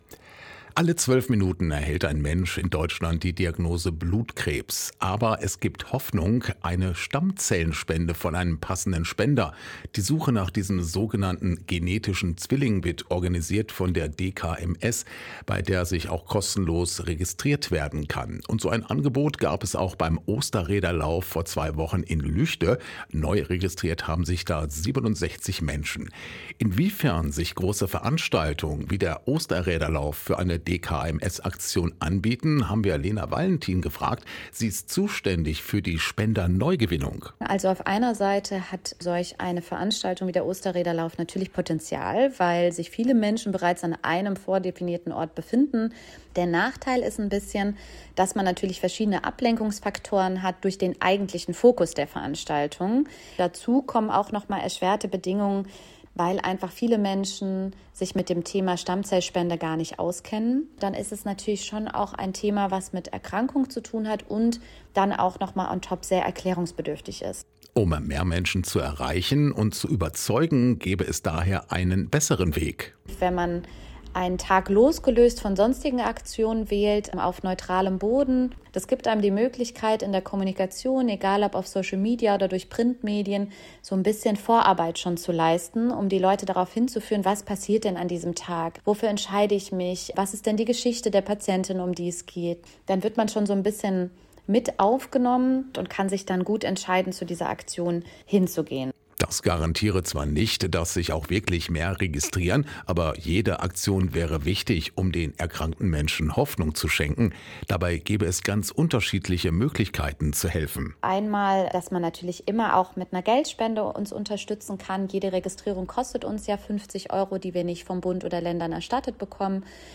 So ein Angebot gab es auch beim Osterräderlaufs vor zwei Wochen in Lügde (ausgesprochen „Lüchde“) – neu registriert haben sich 67 Menschen.